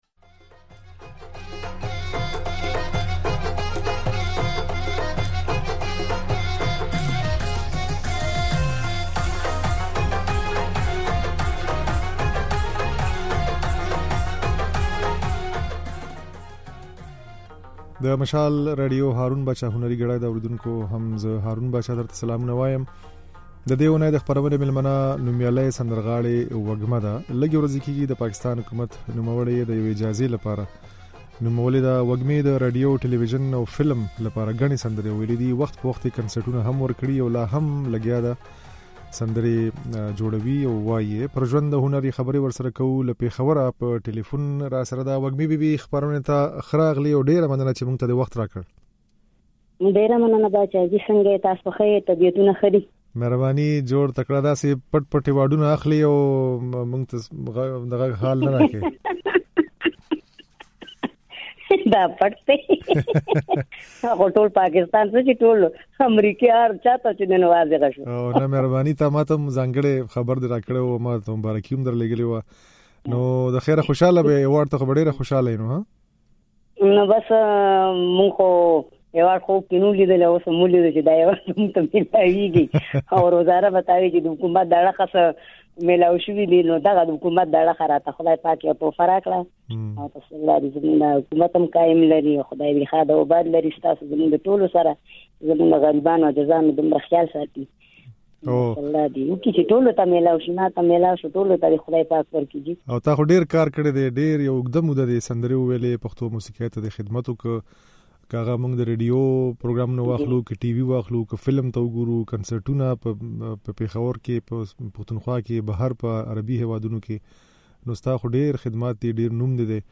د دې اوونې د "هارون باچا هنري ګړۍ" خپرونې مېلمنه نوميالۍ سندرغاړې وږمه ده.